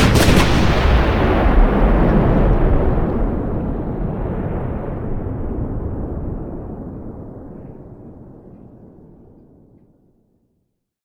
shellexplode.ogg